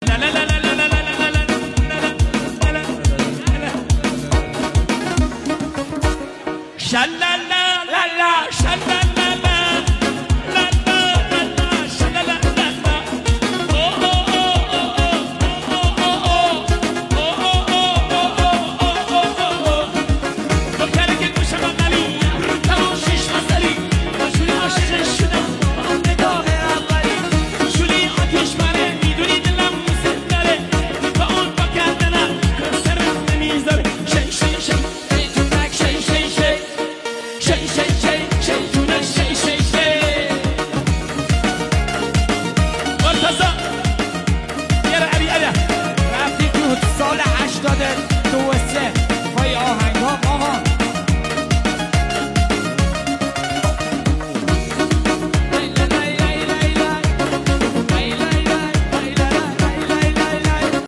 آهنگ شاد کردی
ترانه شاد و ریتمیک کردی
موسیقی شاد کردی (سورانی)
با صدای گرم